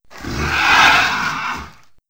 c_glizzom_hit2.wav